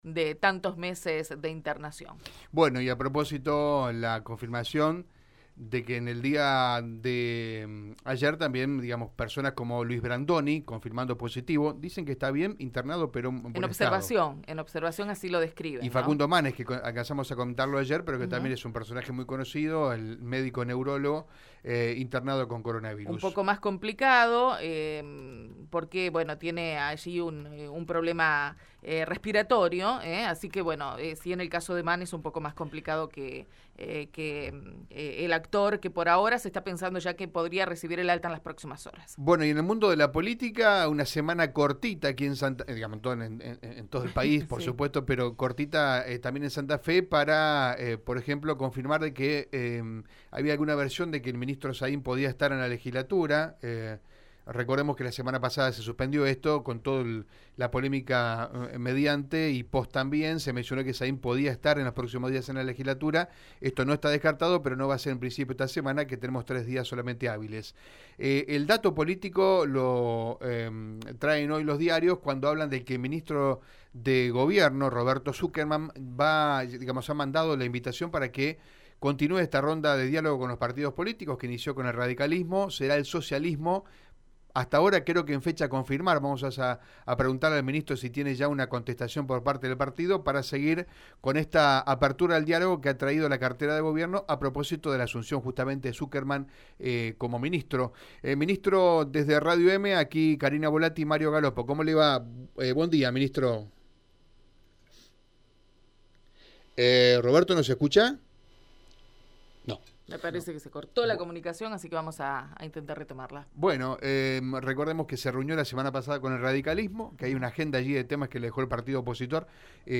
Así lo confirmó el ministro de Gobierno, Justicia, Derechos Humanos y Diversidad de Santa Fe, Roberto Sukerman, en Radio EME.